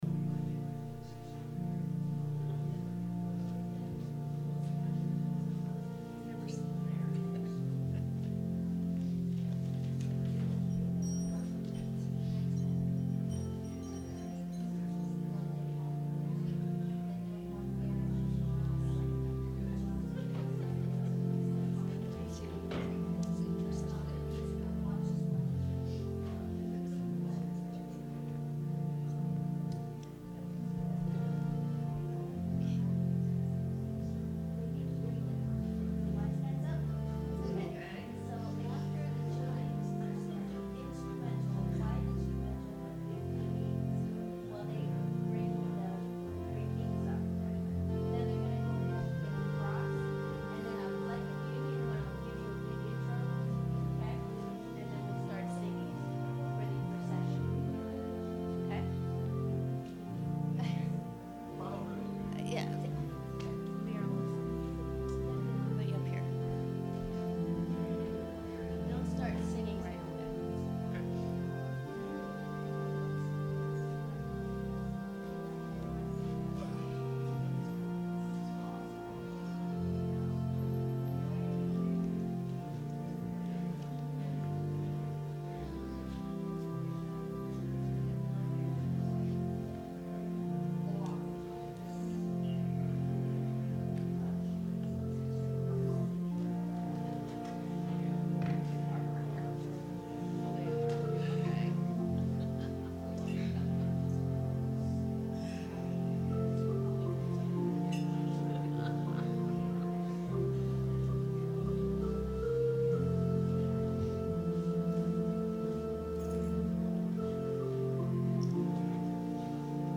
Sermon – January 6, 2019 – Advent Episcopal Church
advent-sermon-january-6-2019.mp3